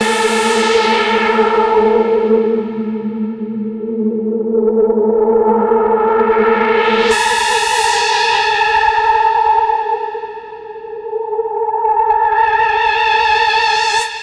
Session 04 - Trance Lead 04.wav